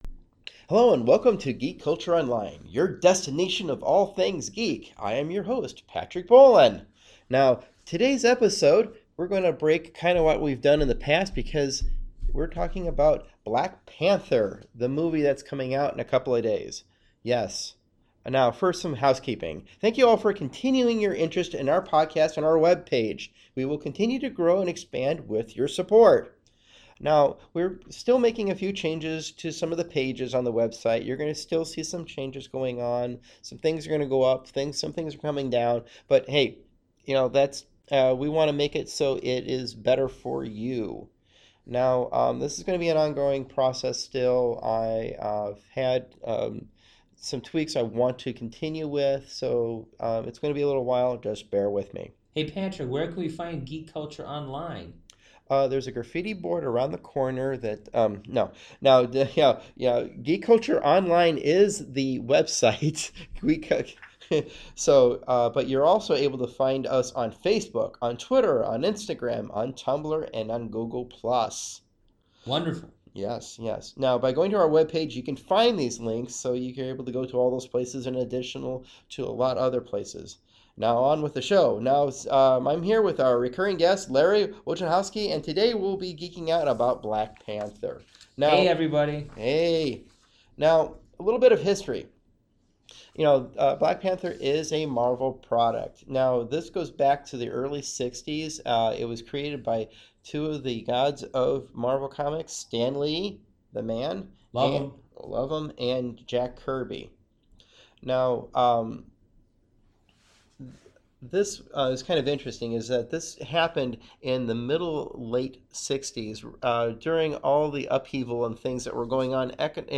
As always we talk too much veer off topic as much as we can and our pronunciations are painful to hear.